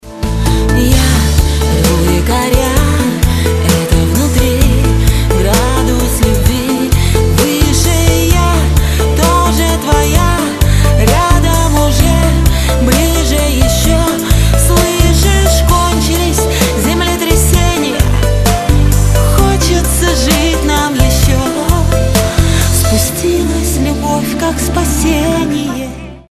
• Качество: 256, Stereo
поп